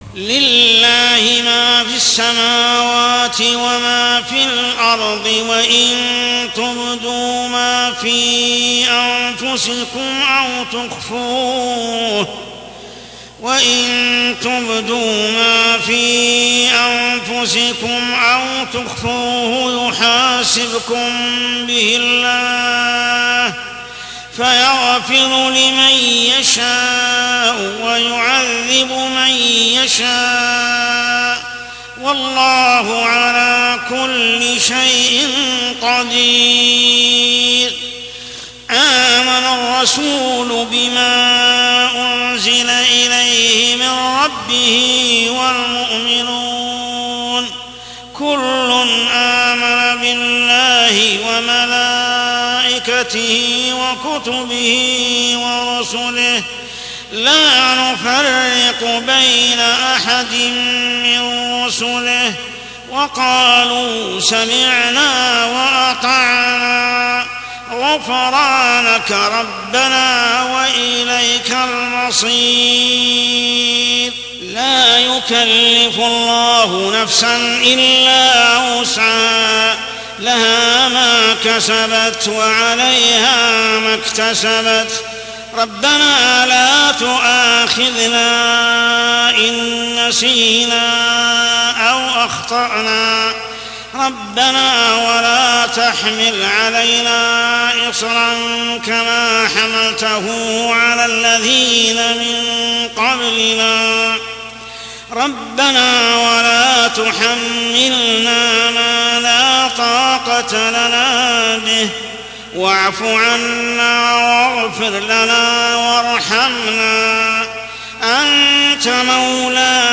عشائيات شهر رمضان 1424هـ سورة البقرة 284-286 | Isha prayer Surah Al-Baqarah > 1424 🕋 > الفروض - تلاوات الحرمين